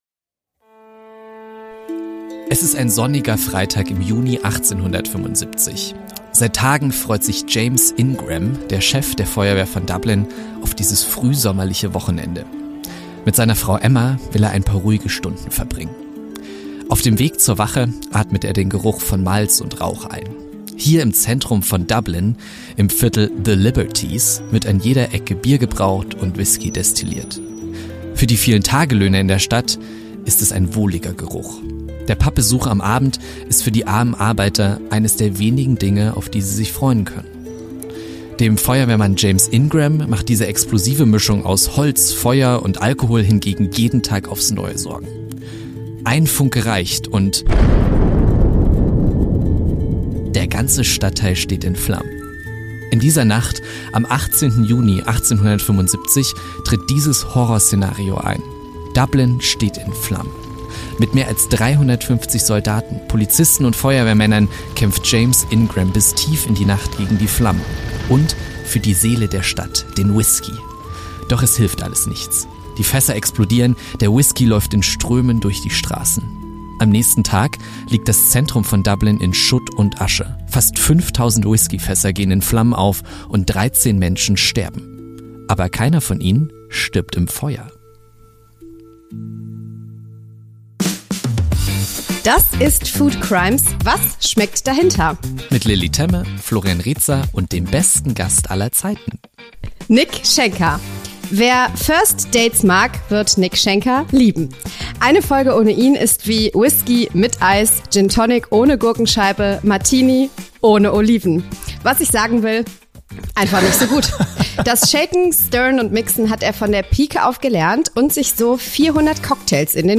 🤓 Jeden Fall besprechen die beiden mit einem passenden prominenten Gast.